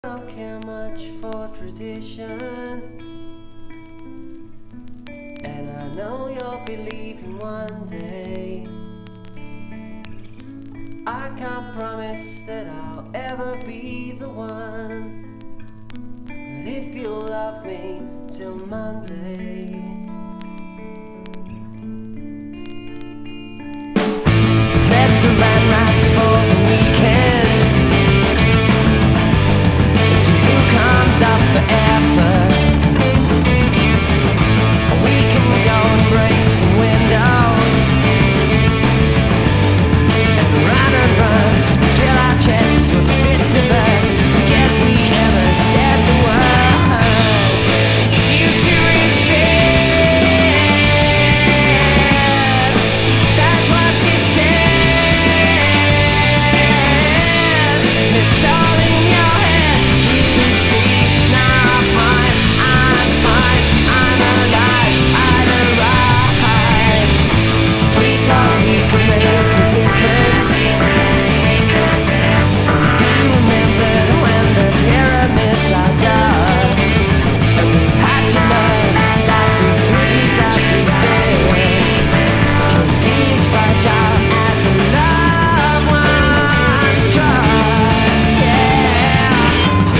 1. 90'S ROCK >
NEO ACOUSTIC / GUITAR POP (90-20’s)